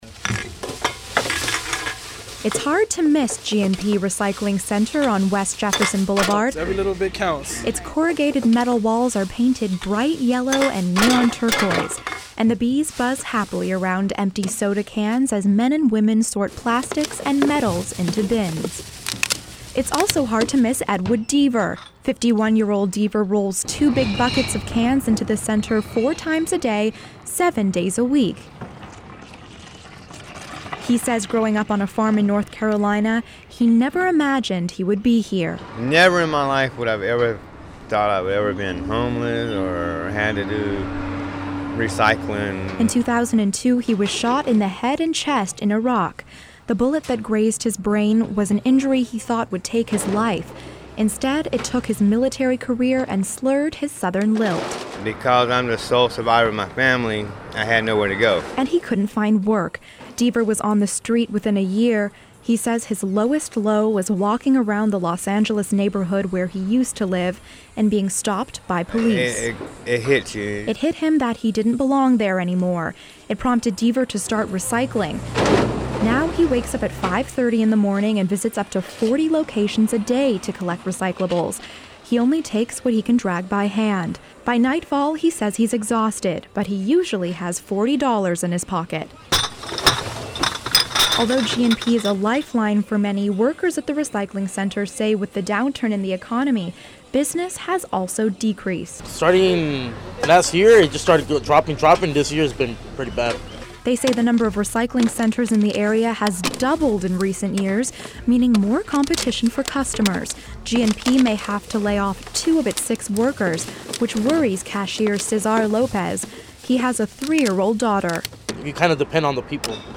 And then there's the sound: an unmistakable noise of bees buzzing happily around empty soda cans and the metallic crunch of aluminum cans and the shattering of glass bottles.